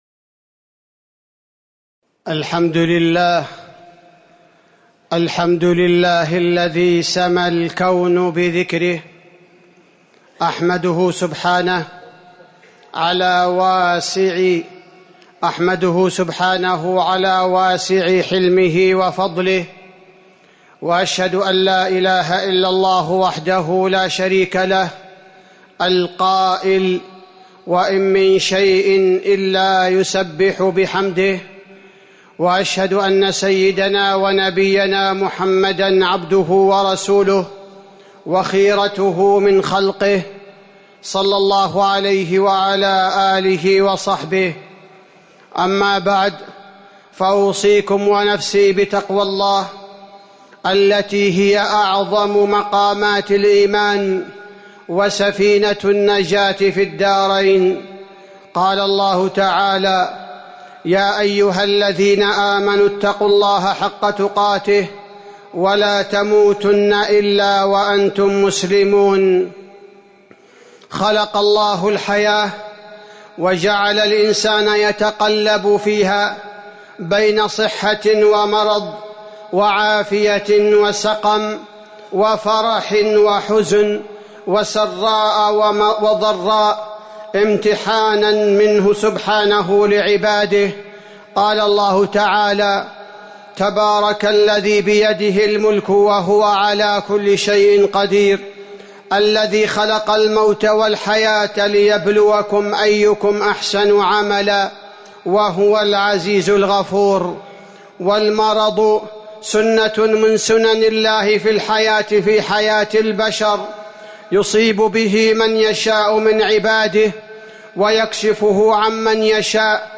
تاريخ النشر ٢٠ جمادى الآخرة ١٤٤١ هـ المكان: المسجد النبوي الشيخ: فضيلة الشيخ عبدالباري الثبيتي فضيلة الشيخ عبدالباري الثبيتي الصحة والمرض